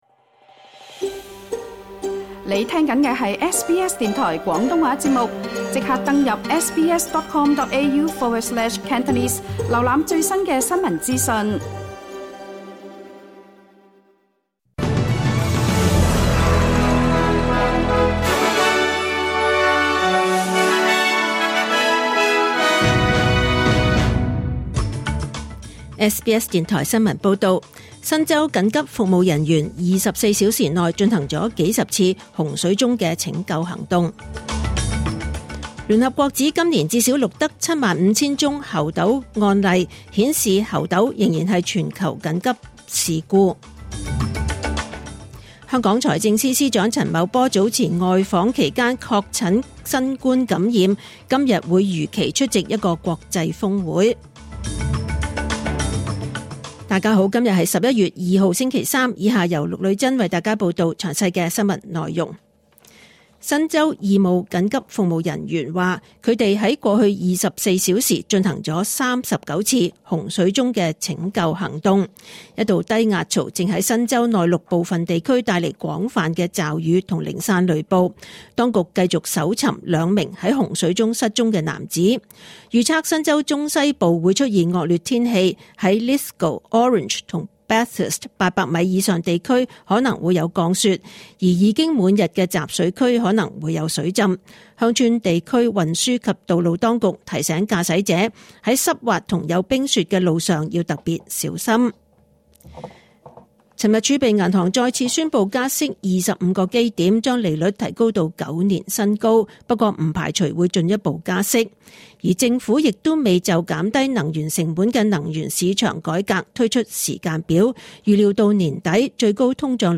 SBS 廣東話節目中文新聞 Source: SBS / SBS Cantonese